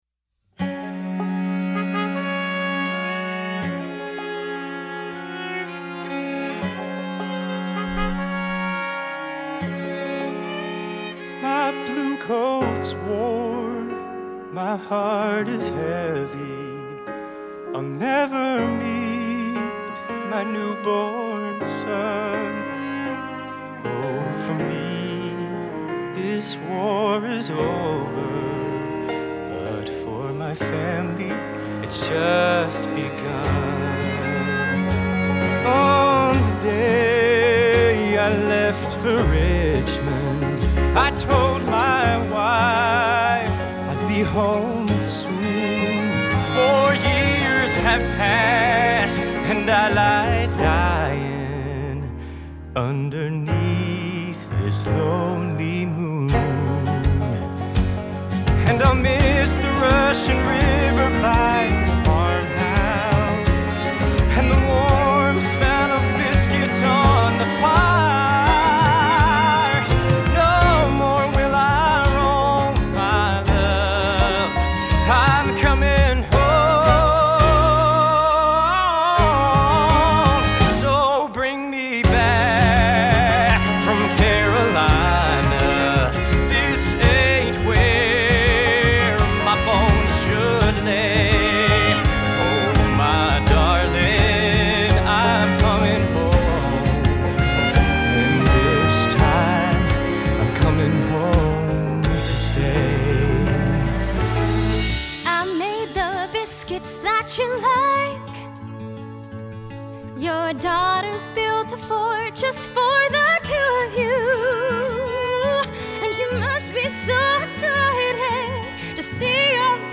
Musical Theater